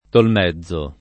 Tolmezzo [ tolm $zz o ]